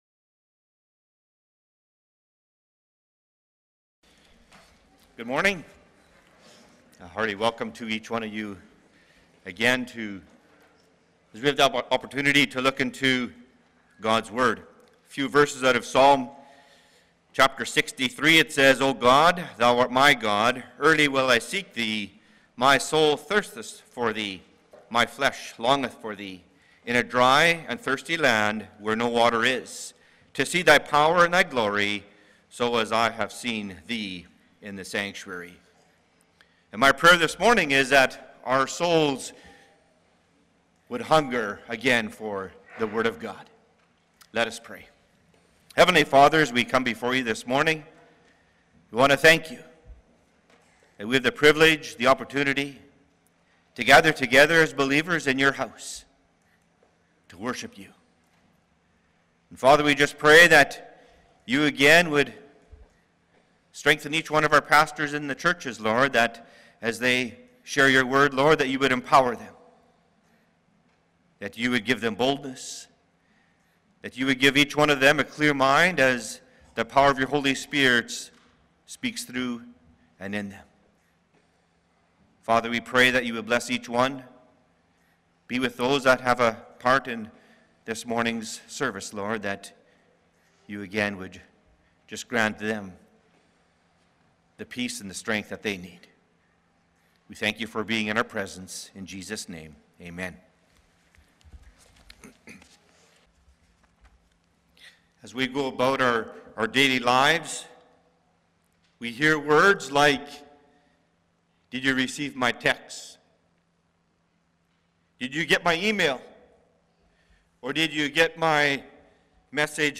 Passage: Exodus 3:1-22 Service Type: Sunday Morning « Church Bible Study